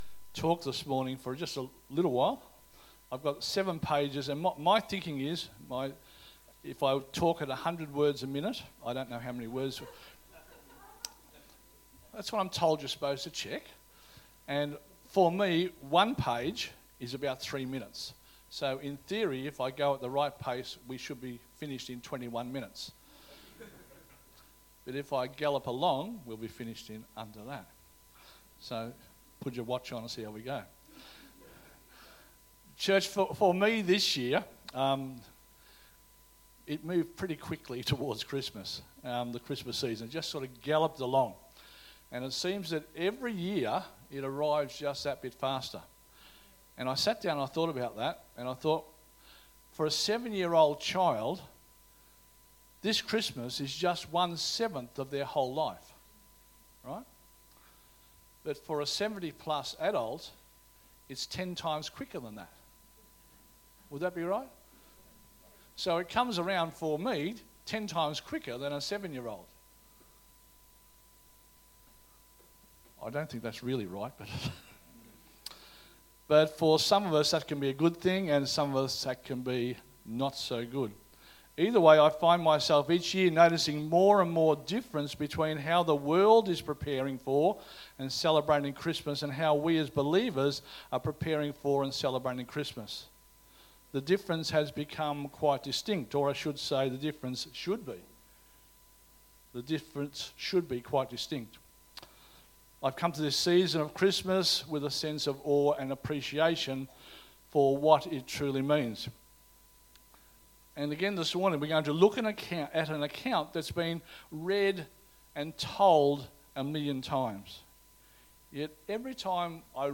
2025 • 17.72 MB Listen to Sermon Download this Sermon Download this Sermon To download this sermon